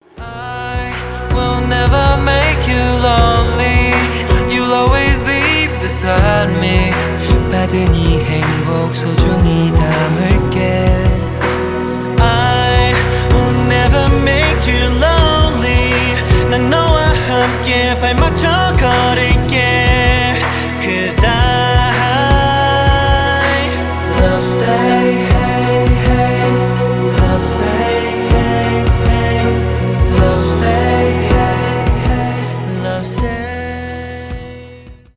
k-pop , поп , романтические